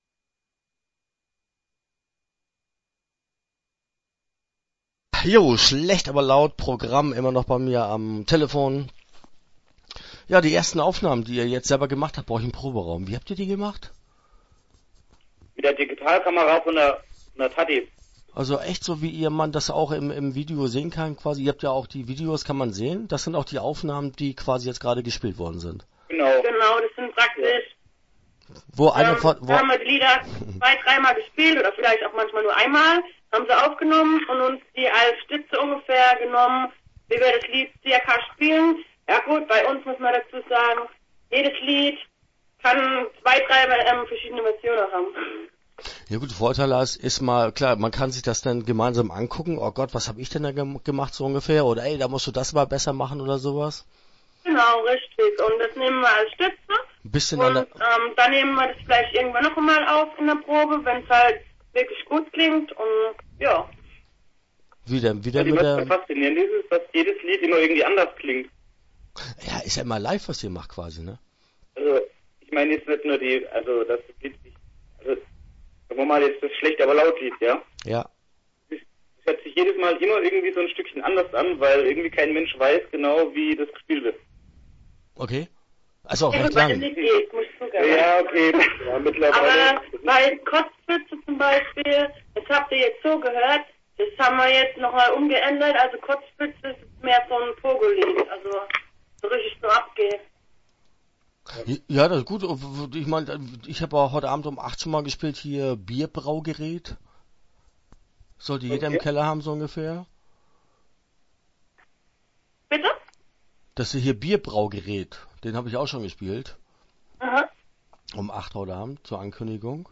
Schlecht aber Laut - Interview Teil 1 (9:06)